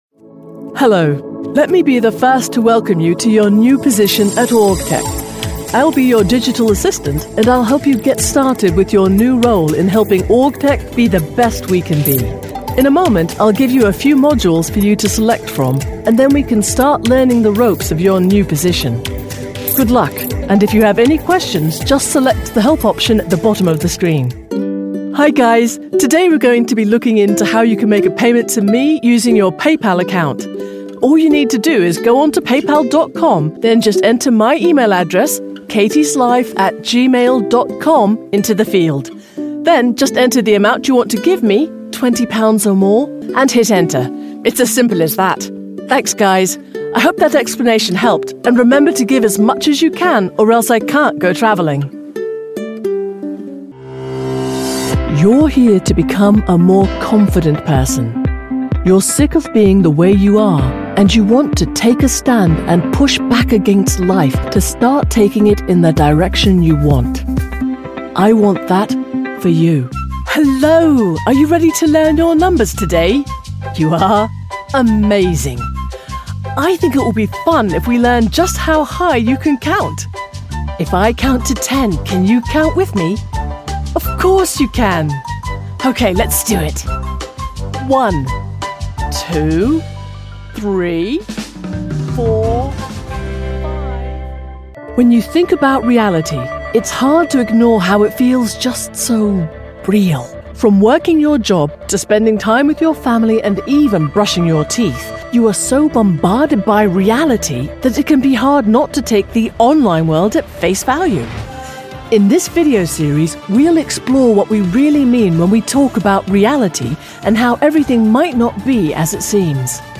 E-learning
I'm a voice actor with a native British accent.
- Recording booth: Whisperroom 5x5
- Microphones: Neumann TLM-103
Contralto
ConversationalFriendlyAuthoritativeWarmExperiencedAssuredEngagingTrustworthyConfidentRelatable